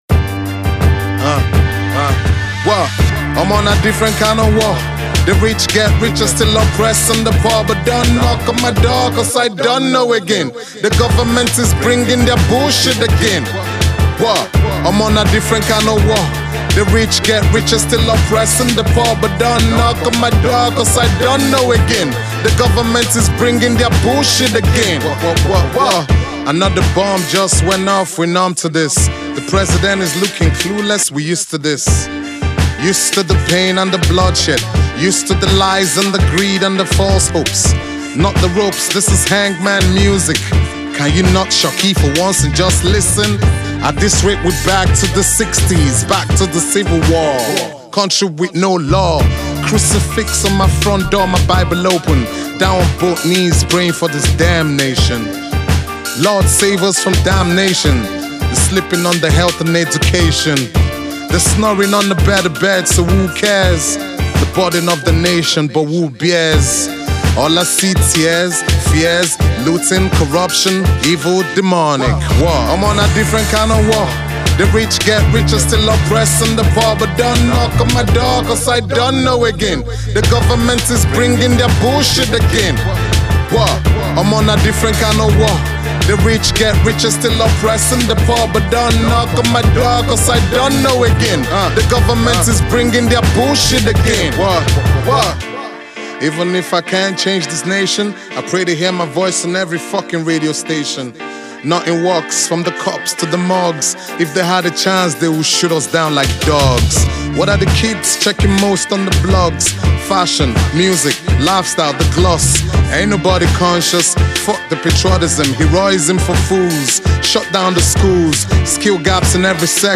Hip-Hop
Its quite a different Rap rune